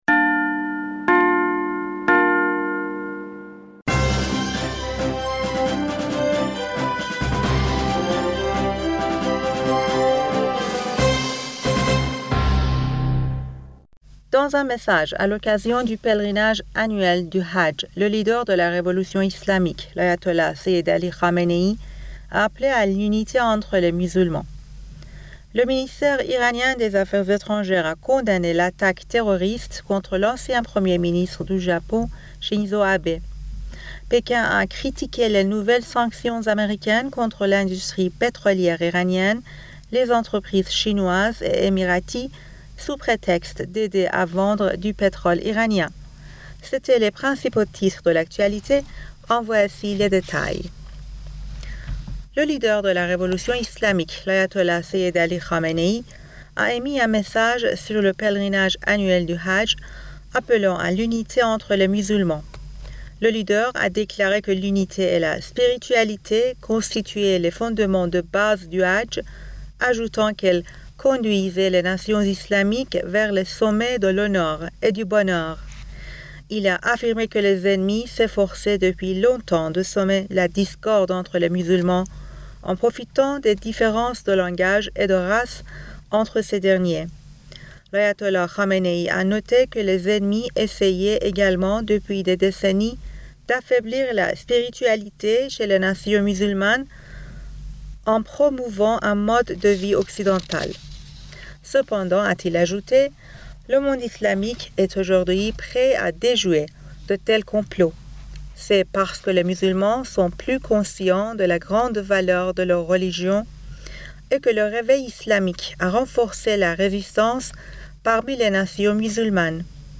Bulletin d'information Du 08 Julliet